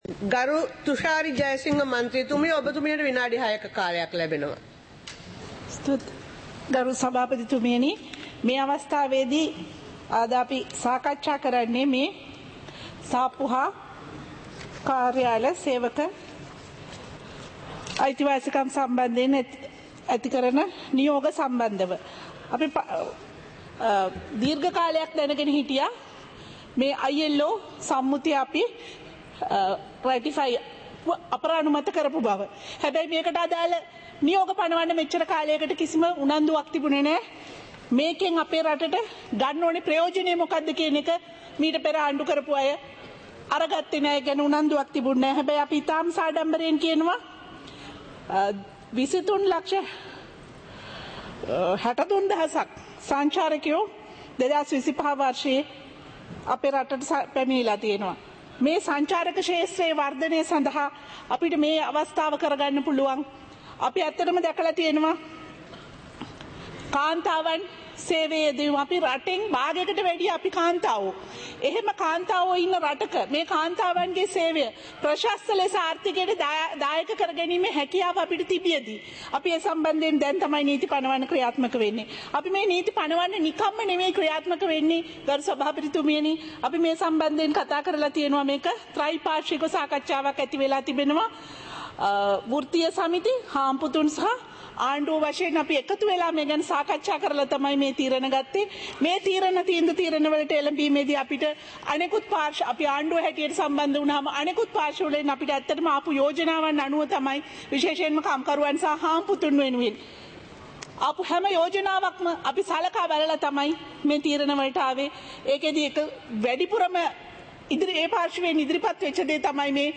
சபை நடவடிக்கைமுறை (2026-01-09)